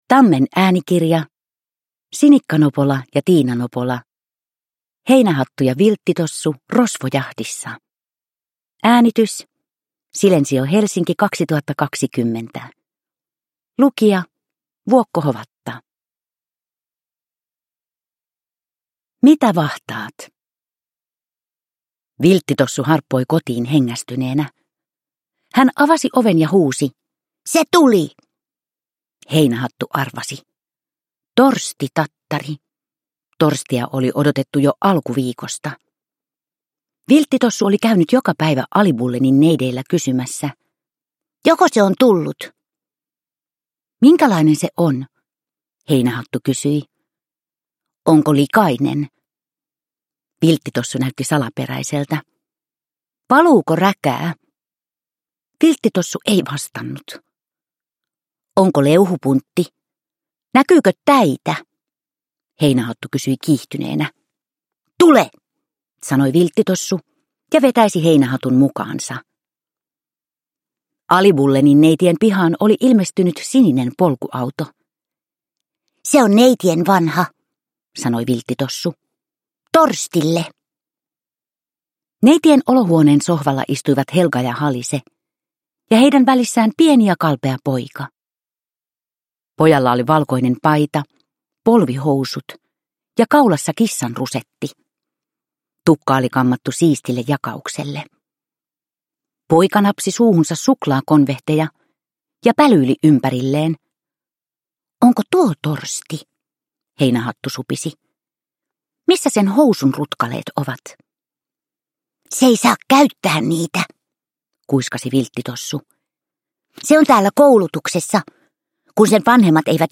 Heinähattu ja Vilttitossu rosvojahdissa – Ljudbok – Laddas ner